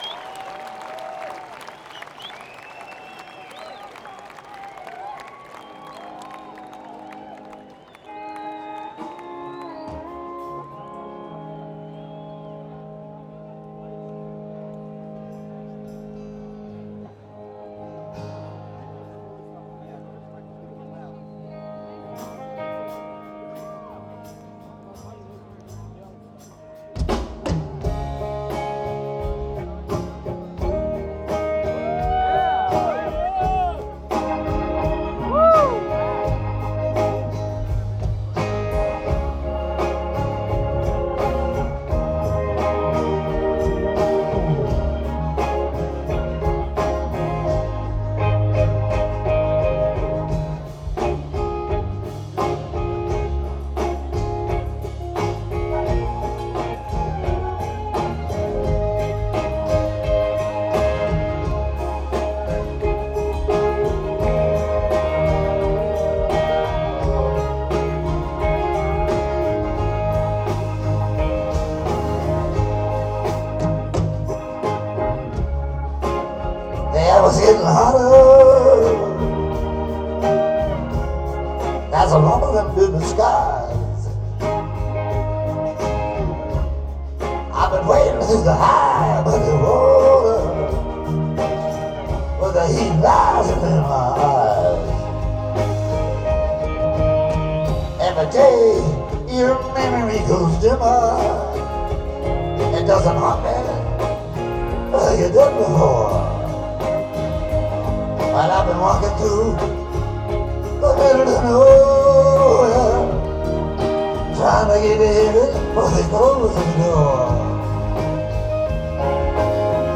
The Pacific Amphitheatre - Costa Mesa, CA